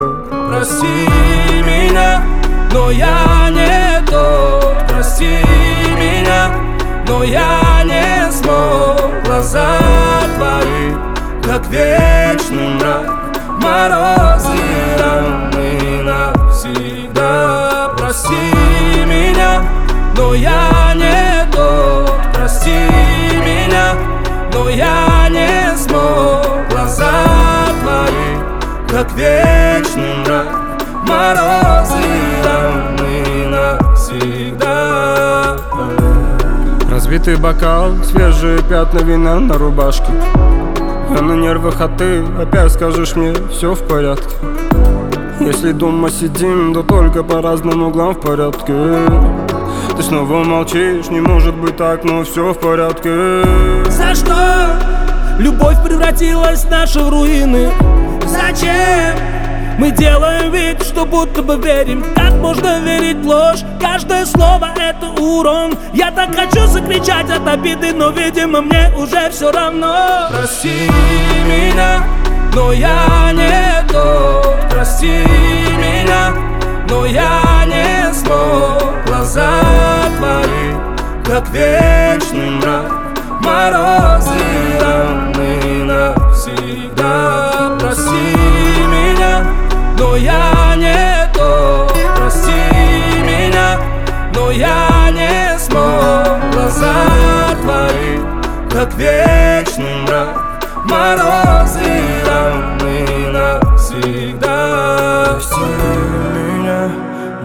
• Качество: 320, Stereo
русский рэп
спокойные